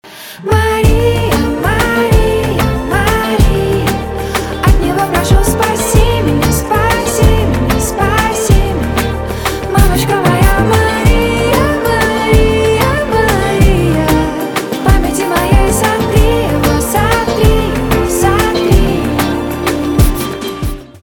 поп
чувственные
гитара , спокойные , барабаны , грустные